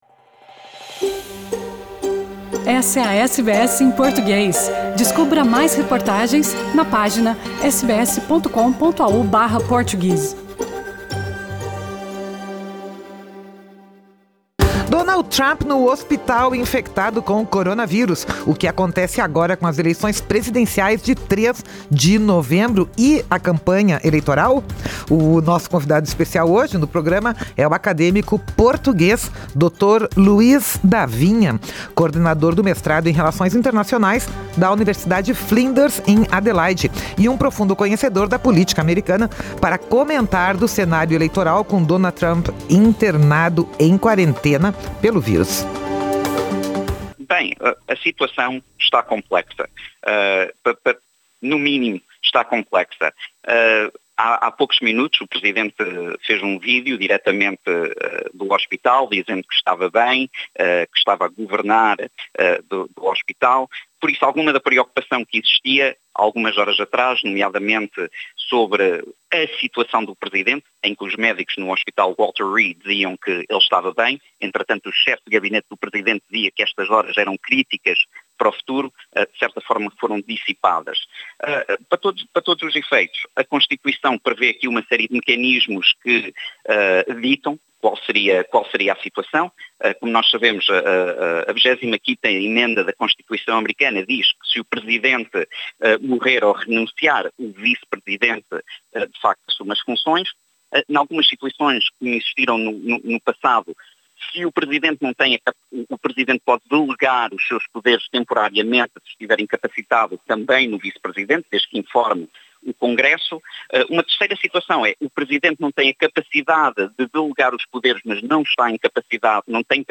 Pode-se ouvir a entrevista completa no podcast junto desta matéria.